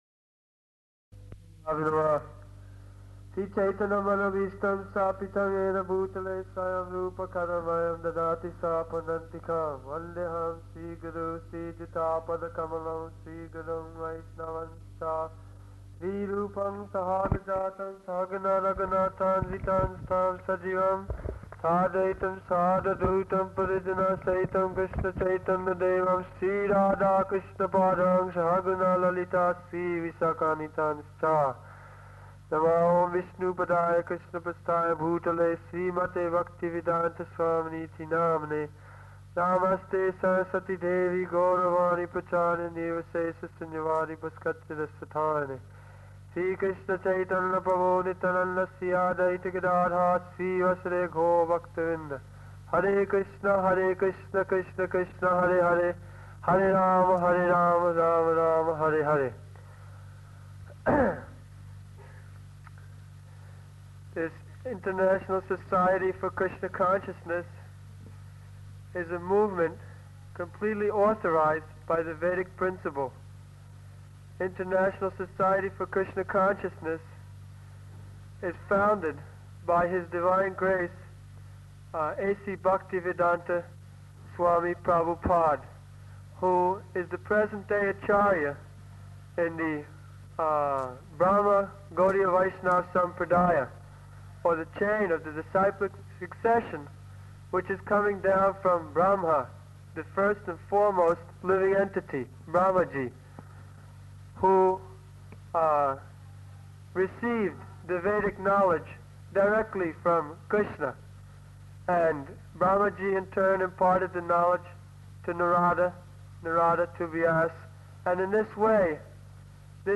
Type: Lectures and Addresses
Location: Allahabad